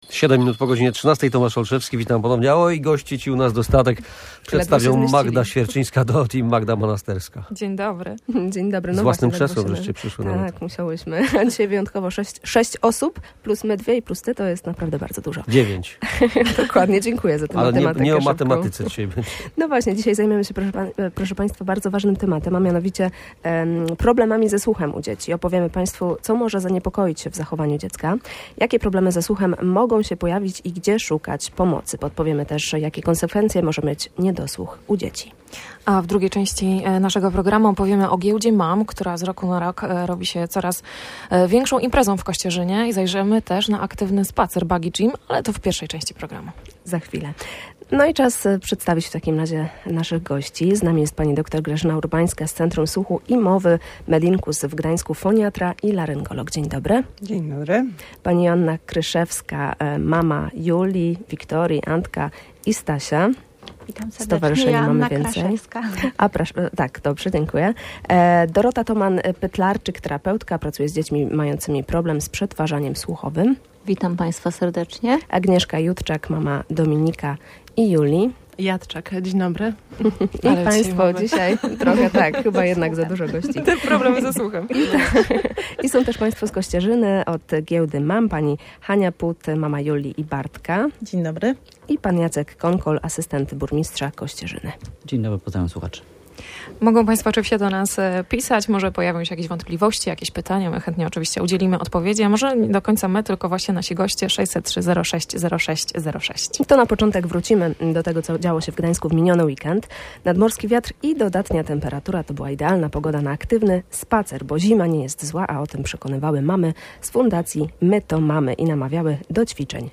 Studio Radia Gdańsk odwiedzili też goście z Kościerzyny – mówili o Giełdzie Mam, która z roku na rok robi się coraz większą imprezą.